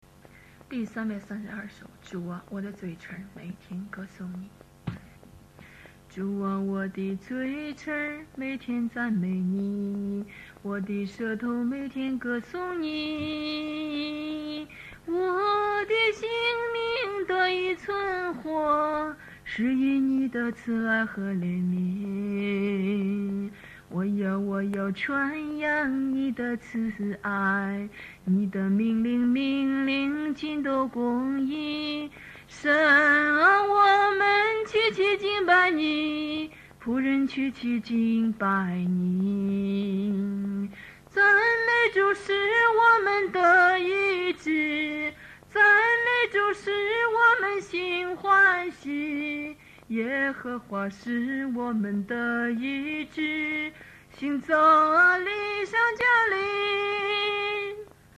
敬拜 120715 上午 10:00